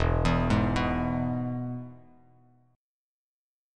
E Chord
Listen to E strummed